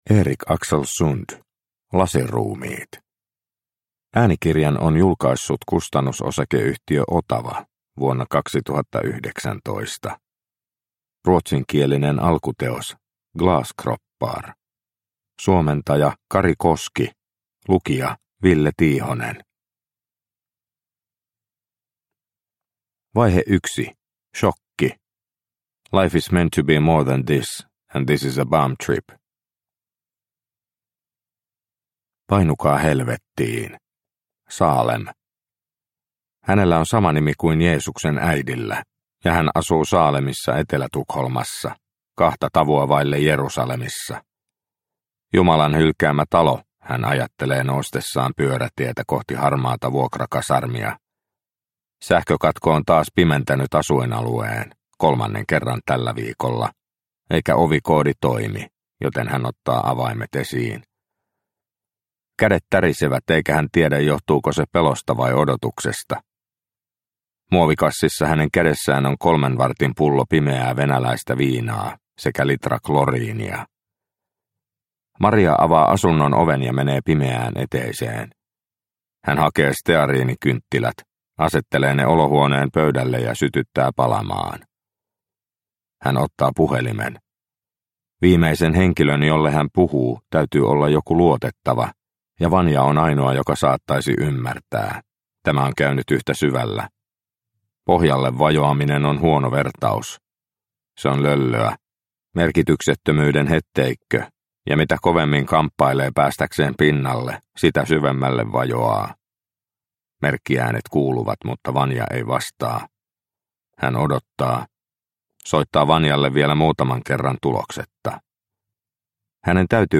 Lasiruumiit – Ljudbok – Laddas ner